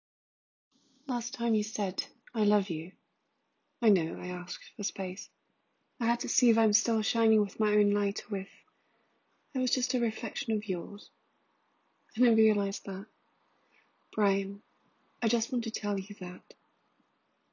a professional voice actor with a warm, expressive voice marked by clarity, emotional depth, and quiet confidence. Her work includes voice animation, TV narration, and commercials, bringing stories to life with a refined, natural delivery.
ENGLISH - NARRATION